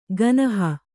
♪ ganaha